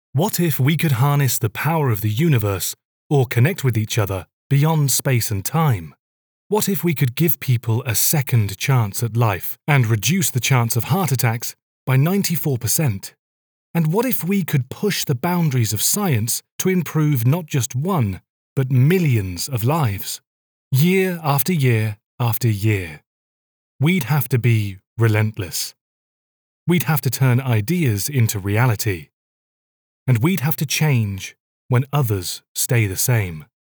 An engaging and warm British voice.
Online Ad
Middle Aged